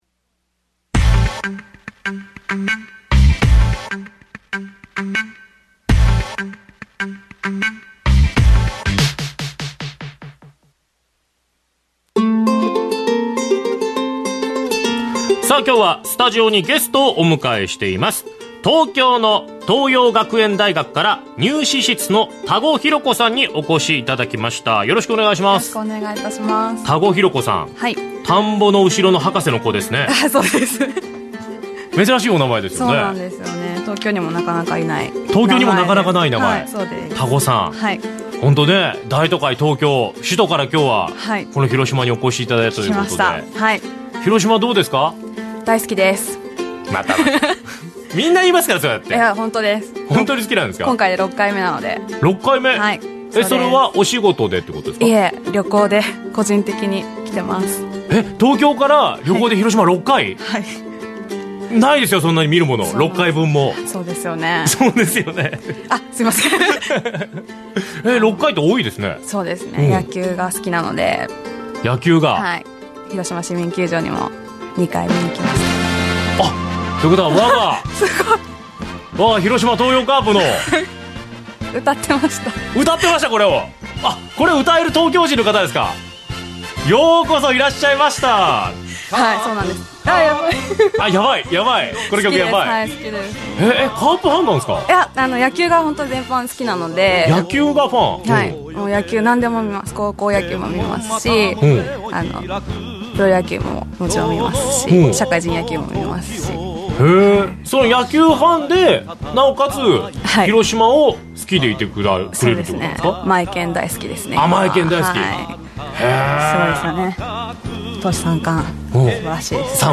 広島県のラジオ局・RCC中国放送（AM1350KHz）で、地方入試ＰＲ。